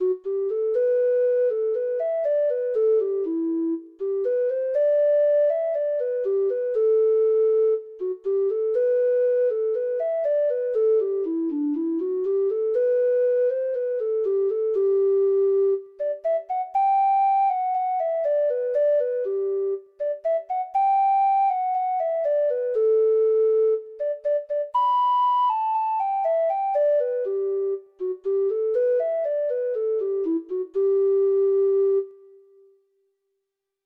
Traditional Trad. DRIMOLEAGUE FAIR (Irish Folk Song) (Ireland) Treble Clef Instrument version
Traditional Music of unknown author.
Irish